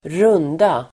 Uttal: [²r'un:da]